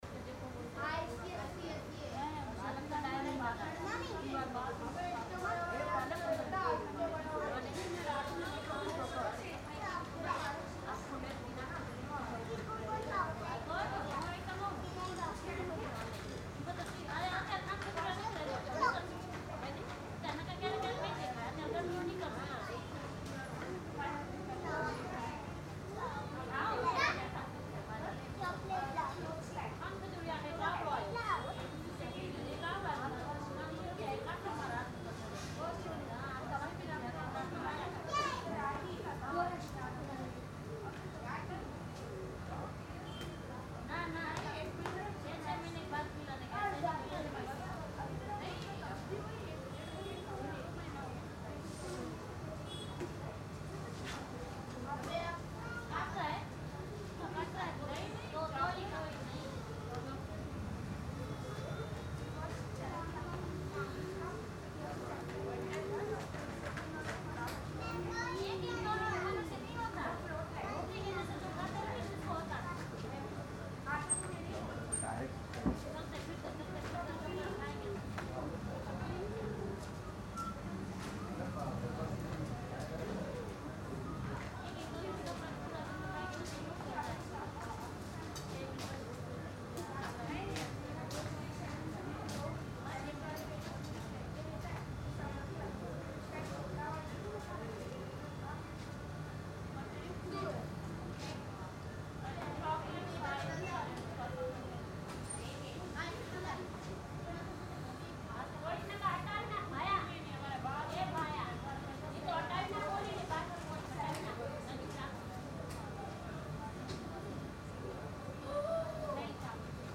Female Murmur-AMB-30
• Any project needing natural female-crowd chatter without overpowering dialogues
A natural ambience featuring a group of women engaged in light conversations, soft laughter, and gentle background movement. The sound captures a realistic public environment with warm human presence, making the scene feel lively yet non-distracting.
Human Ambience / Crowd
Female Group Talking
Mild–Moderate
Soft, warm, natural
Thirtyone-female-crowd-talk-1.mp3